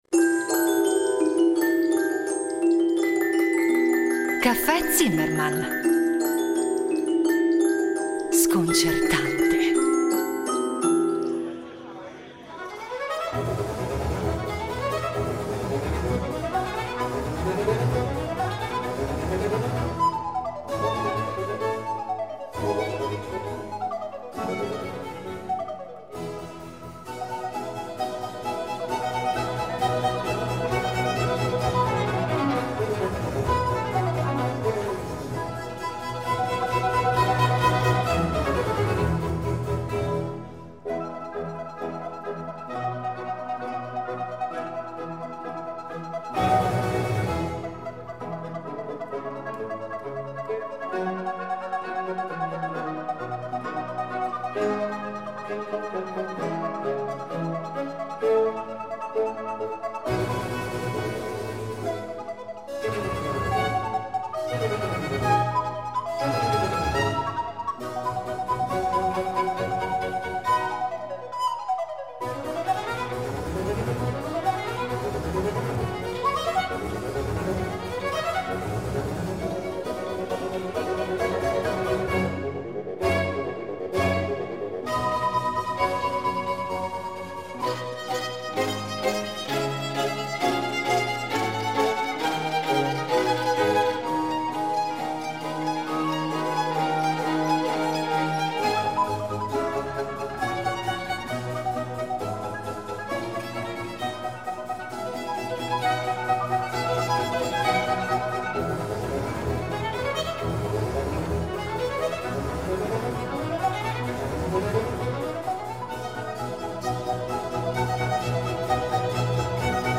A dirigere Il Giardino Armonico dal 1989 Giovanni Antonini , tra i membri fondatori, che ai microfoni di Rete Due ne ripercorre la storia.
A guidarci le musiche della rilevante quanto varia discografia dell’ensemble (proposta in ordine cronologico).